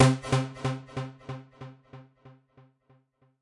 描述：用reFX Vanguard制作的旋律。声音一。
Tag: 标题 DJ 高潮 跟踪 恍惚门 捻线机 神情恍惚 舞蹈 melodics 旋律